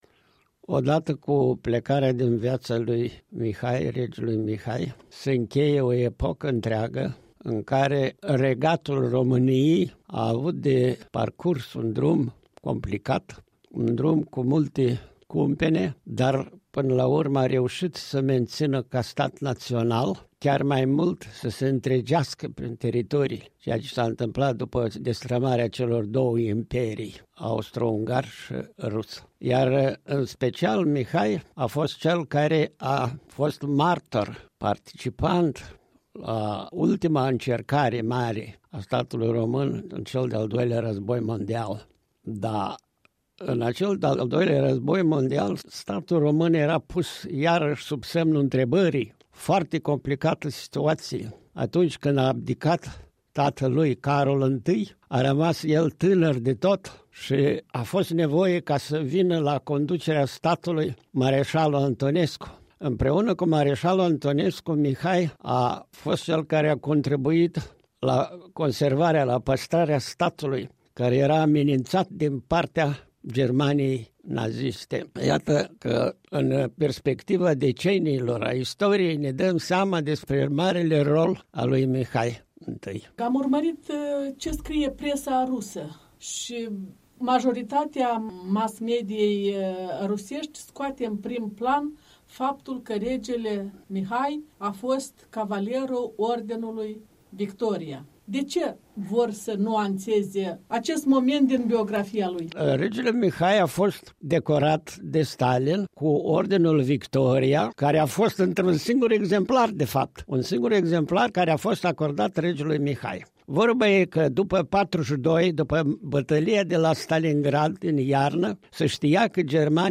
Regele Mihai la Cimitirul de la Țiganca